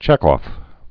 (chĕkôf, -ŏf)